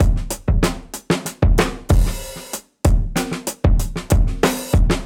Index of /musicradar/dusty-funk-samples/Beats/95bpm
DF_BeatB_95-04.wav